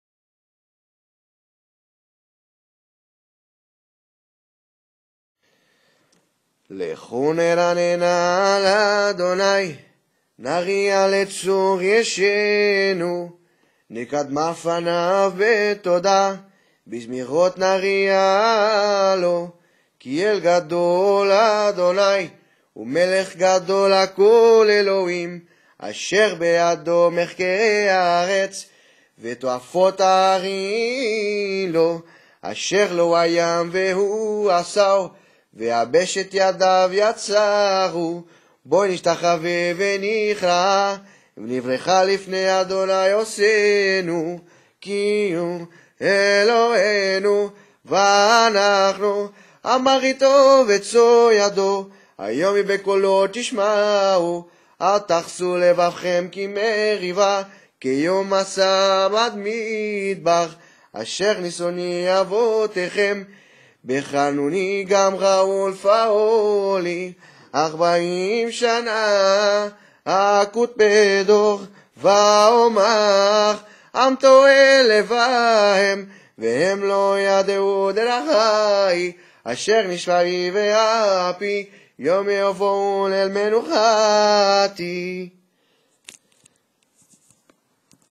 MP3-Tehillim-95-Sephardic.mp3